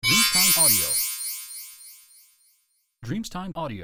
Carillon magico della colata di incantesimo di aspetto
• SFX